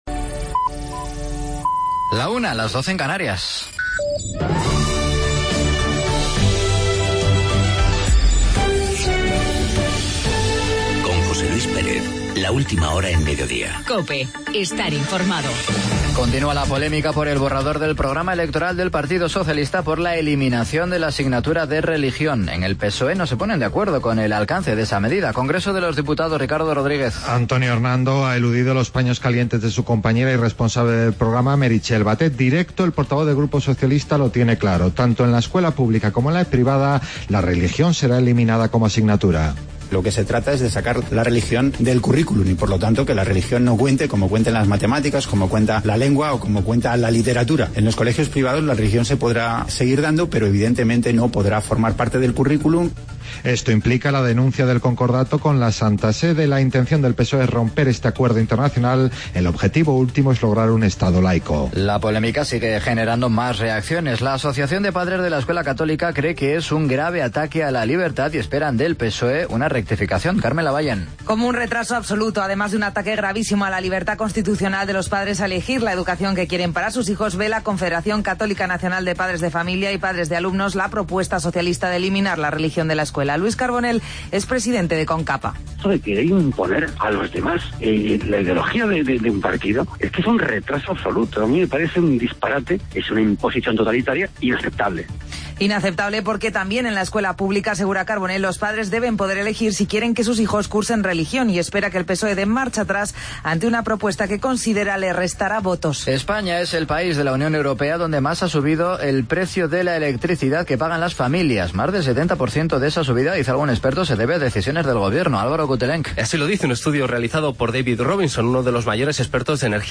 AUDIO: Entrevistamos